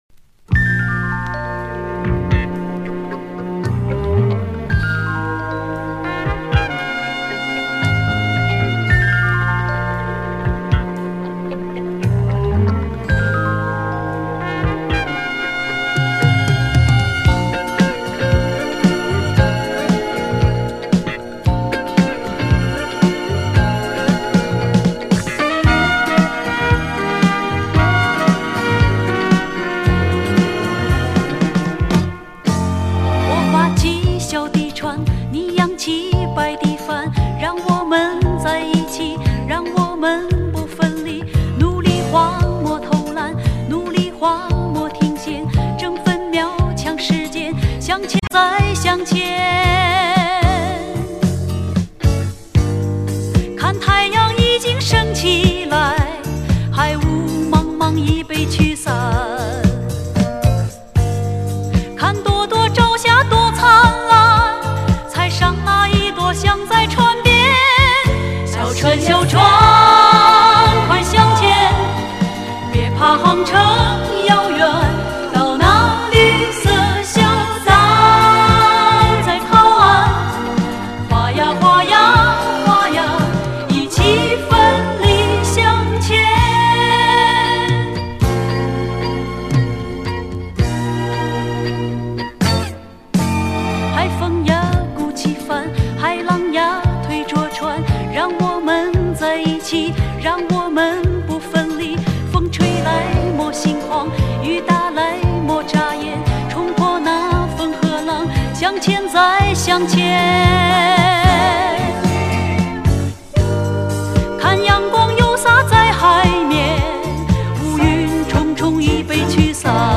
很好听的一张专辑，音质不错，收下了。